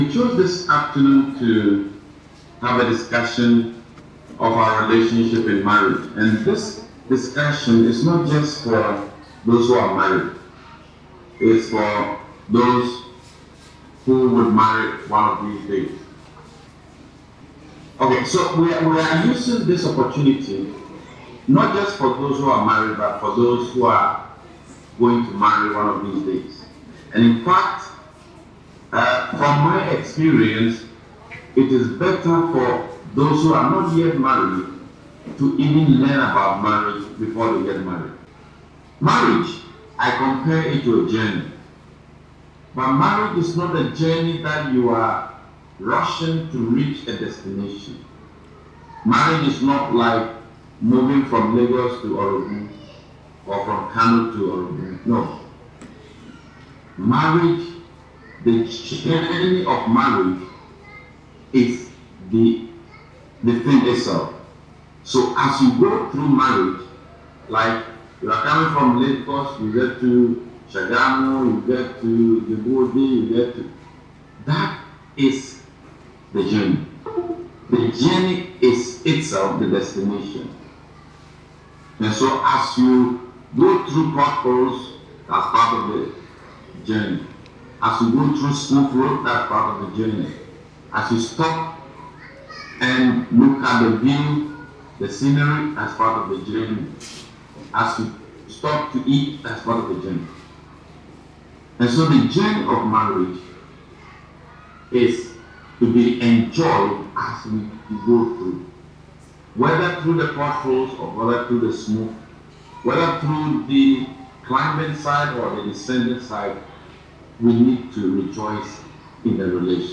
and was held via Zoom. The session was an inspiring and insightful gathering, focused on addressing the complexities of marriage and providing practical guidance for navigating the challenges couples face in their journey together.